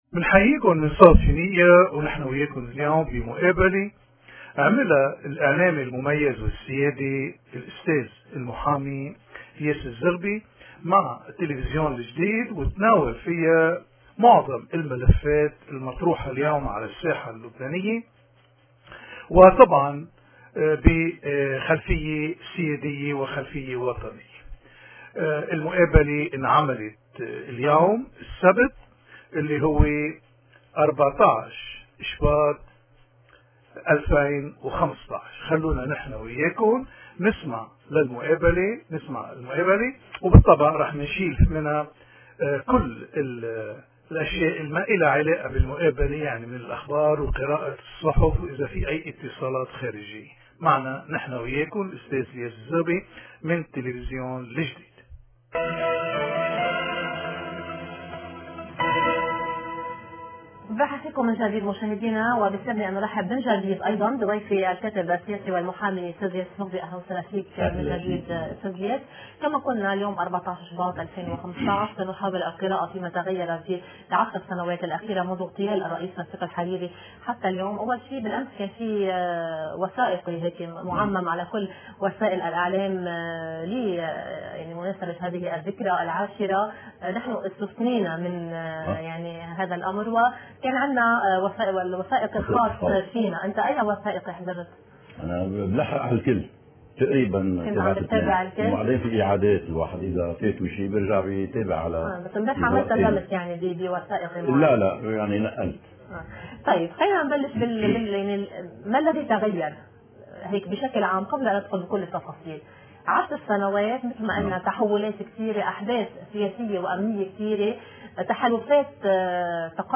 من تلفزيون الجديد/مقابلة